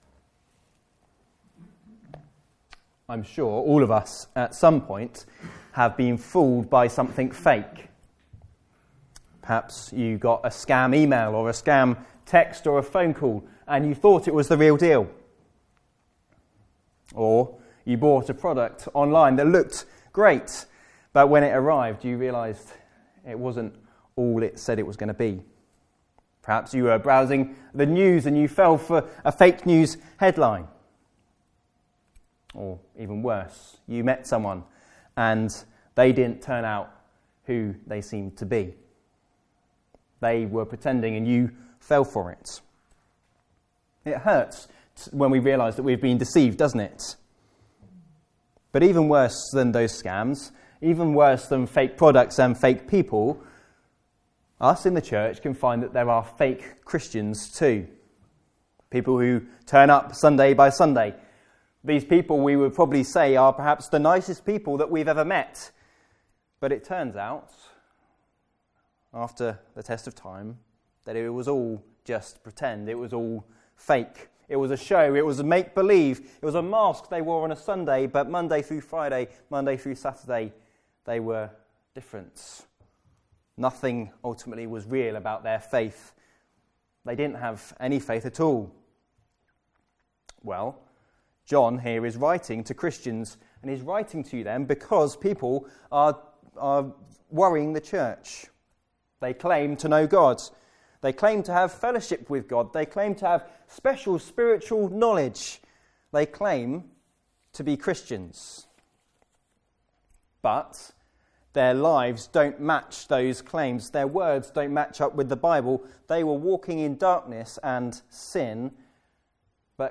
Passage: 1 John 2: 1-6 Service Type: Afternoon Service